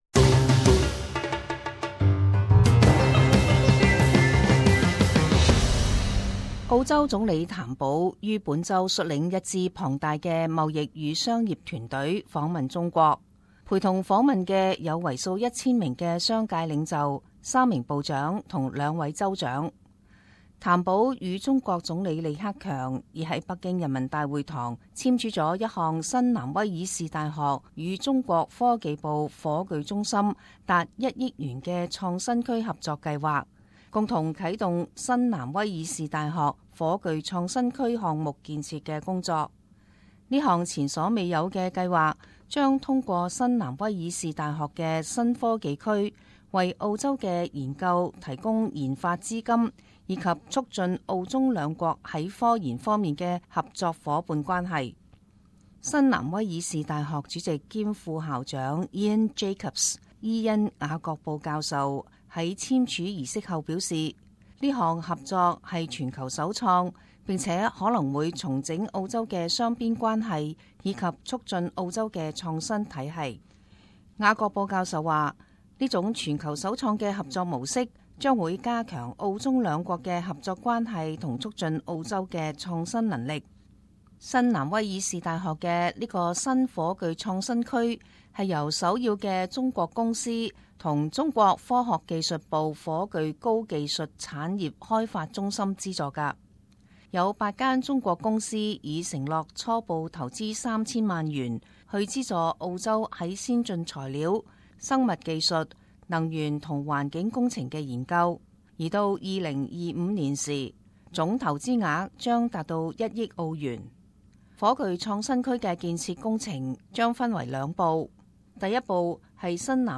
时事报导 - 新南威尔士大学与中国合作建设火炬创新区